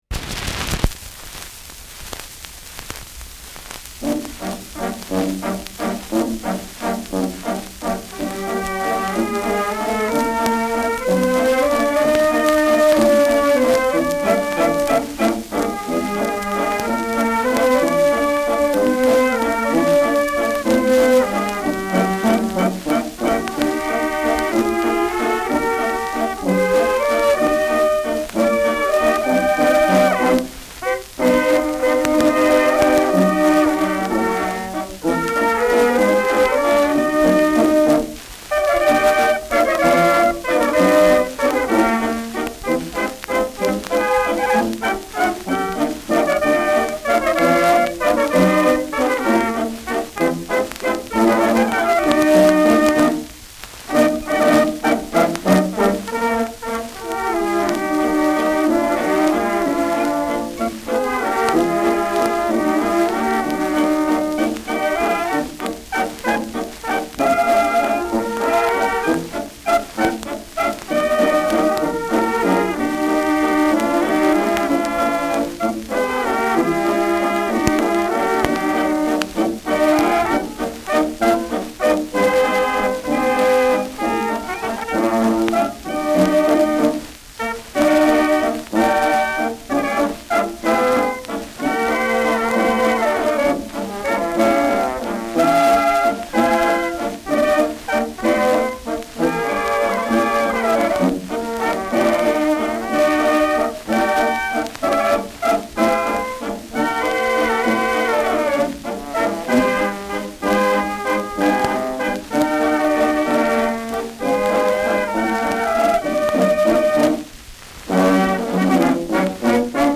Schellackplatte
Gelegentlich stärkeres Knacken : Nadelgeräusch : Teils leicht verzerrt
[unbekanntes Ensemble] (Interpretation)
[Prag] (Aufnahmeort)